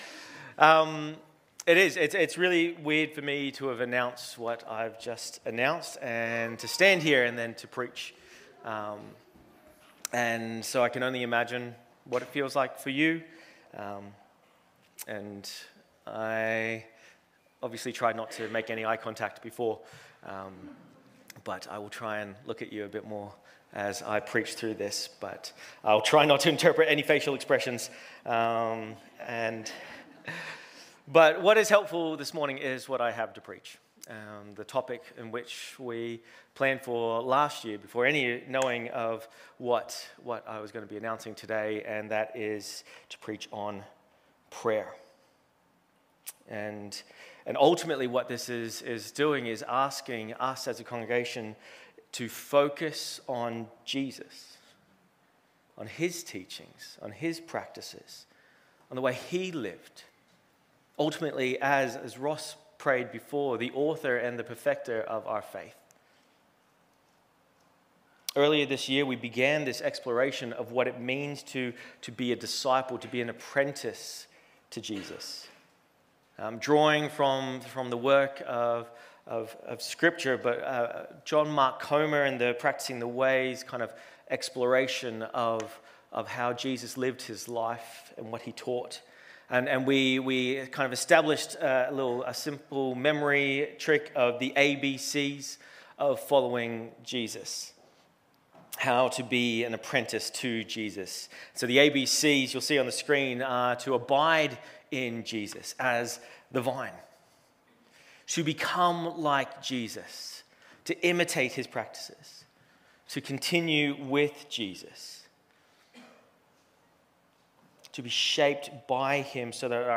Sermons | Titirangi Baptist Church
Guest Speaker